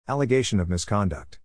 発音：/ˌæləˈgeɪʃən/